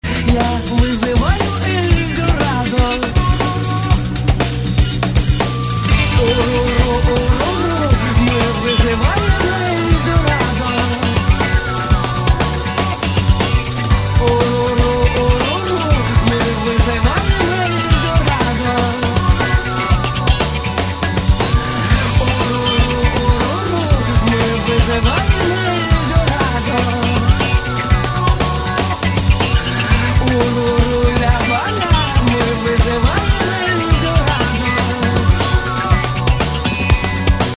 русский рок
фолк-рок
инди рок
Альтернативный рок
Веселый русский рок